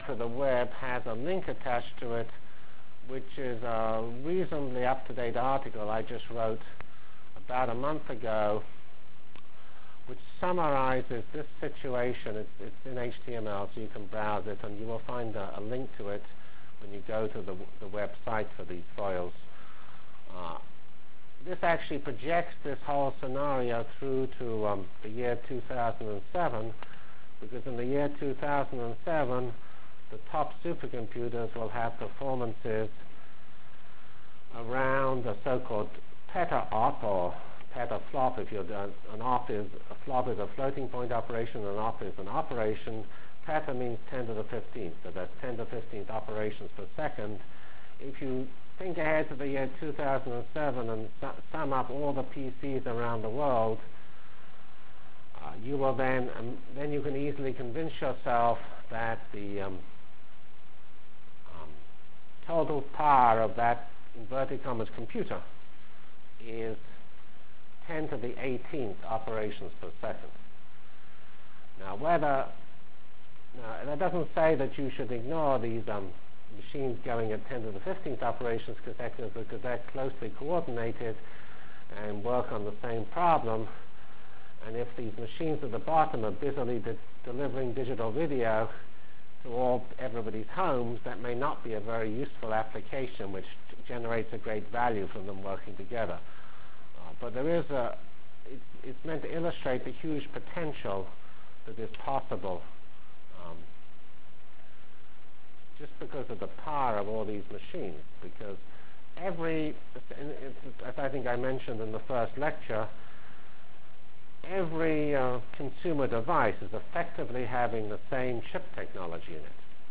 From Feb 5 Delivered Lecture for Course CPS616 -- Java as a Computional Science and Engineering Programming Language CPS616 spring 1997 -- Feb 5 1997. *